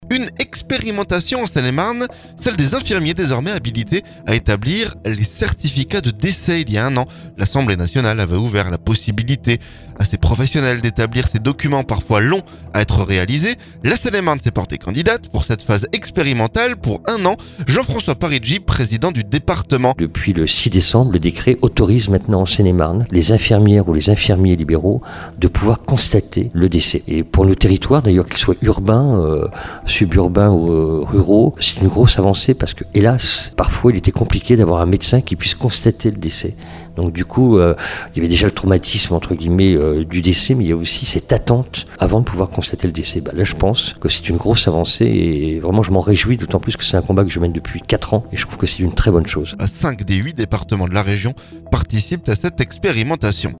Jean-François Parigi, président du Département au micro d’Oxygène.